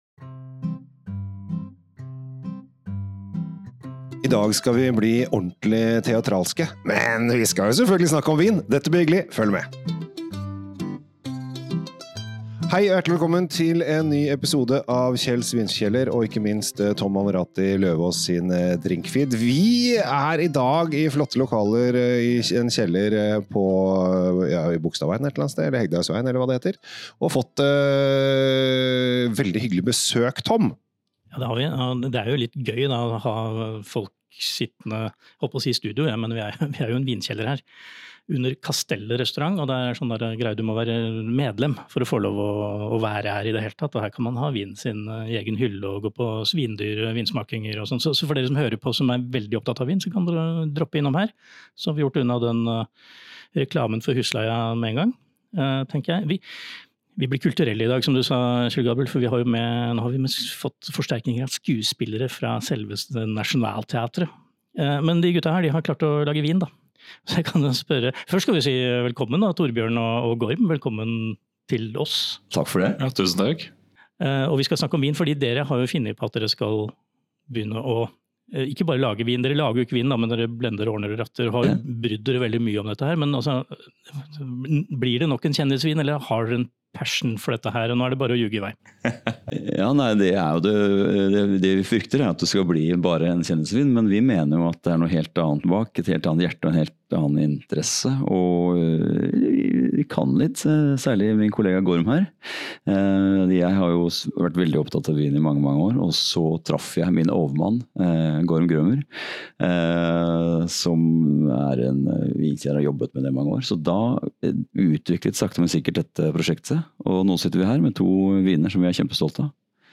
Det blir både monologer og improviserte replikkvekslinger underveis når teppet går opp for første akt av Monologue i vinkjelleren.